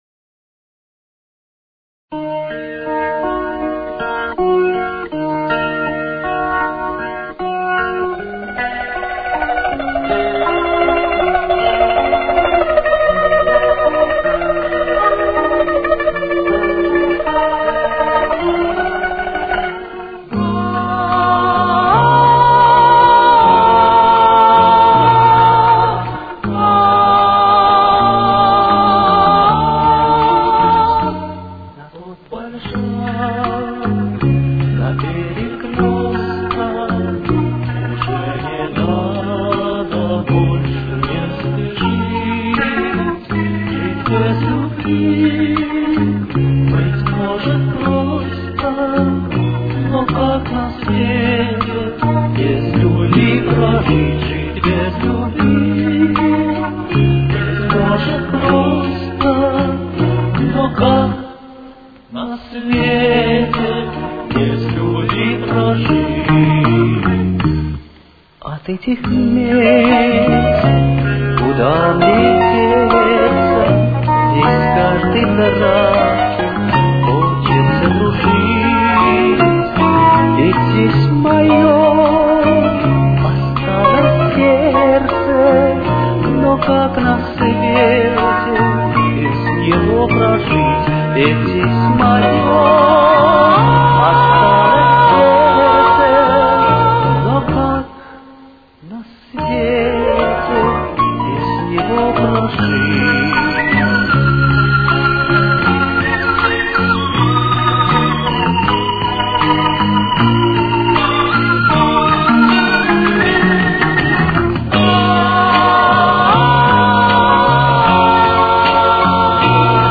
Ми-бемоль минор. Темп: 78.